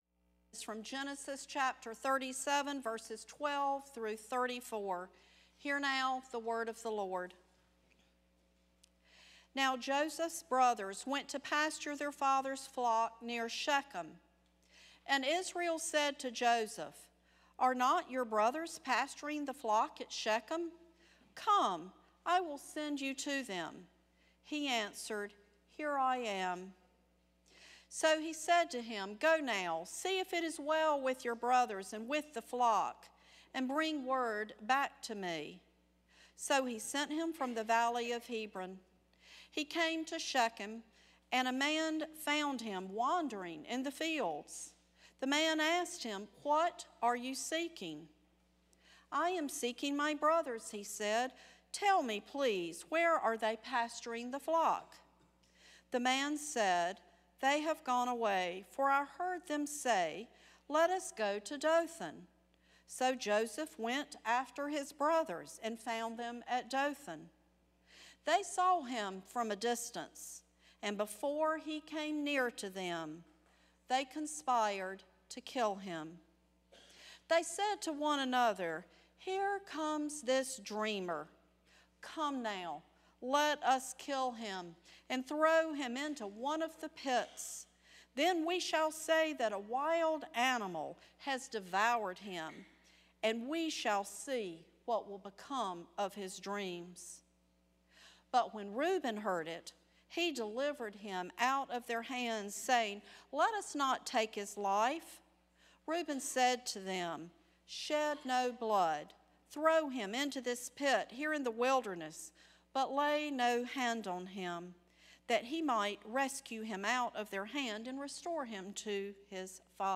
First Cary UMC's First Sanctuary Sermon